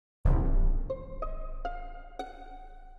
Among Us Report Body Sound Button | Sound Effect Pro
Instant meme sound effect perfect for videos, streams, and sharing with friends.